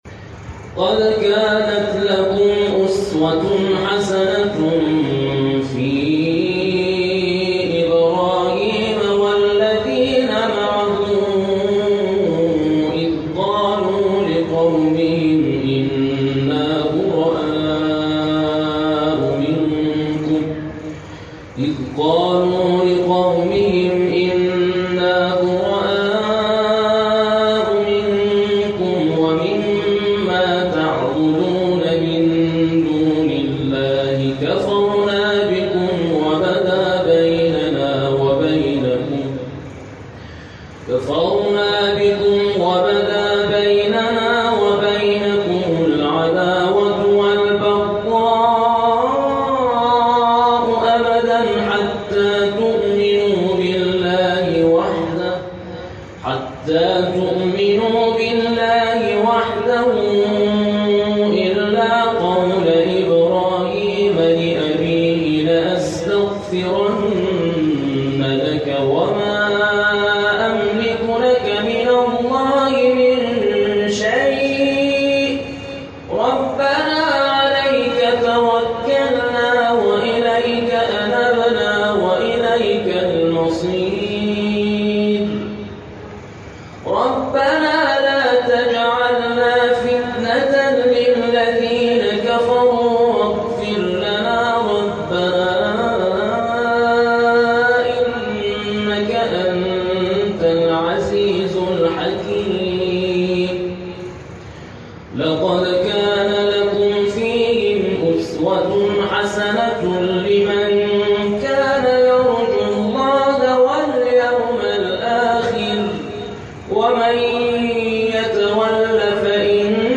شبکه اجتماعی: مقاطعی صوتی از تلاوت قاریان ممتاز کشور را می‌شنوید.